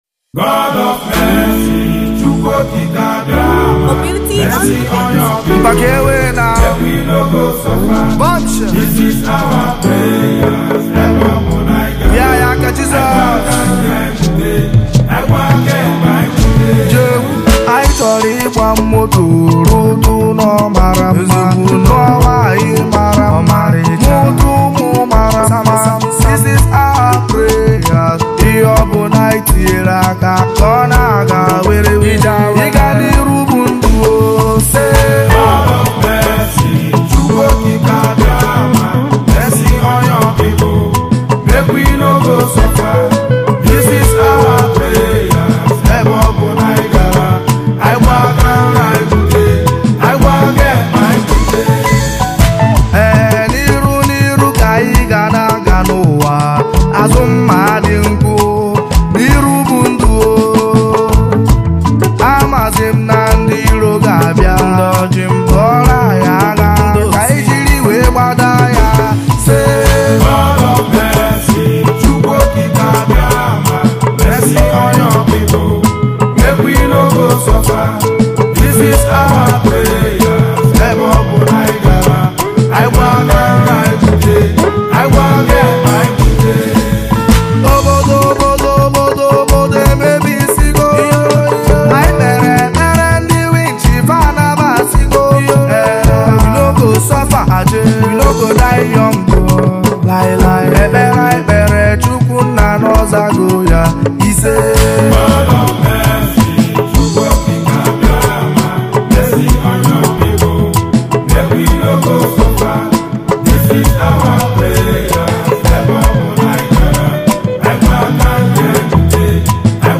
gospel worship single